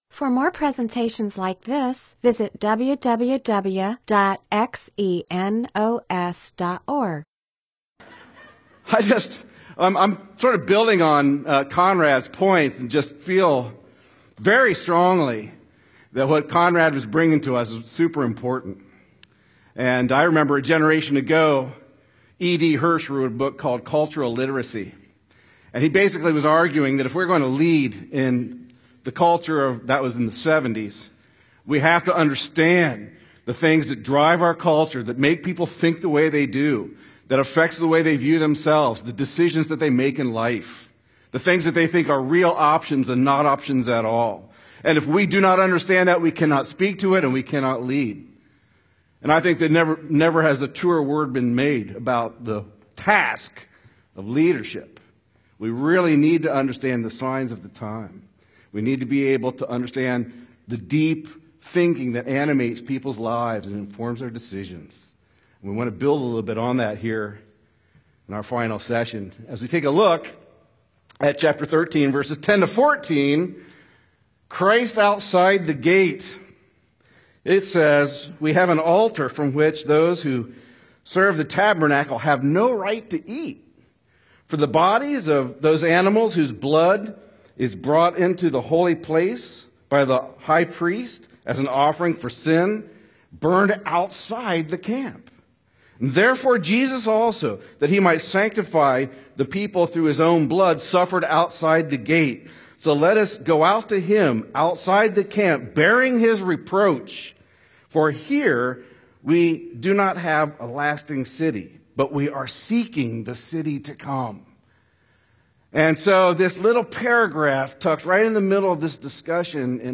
MP4/M4A audio recording of a Bible teaching/sermon/presentation about Hebrews 13:10-14; Hebrews 8:4-5; Hebrews 11:8-10.